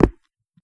footstep3.wav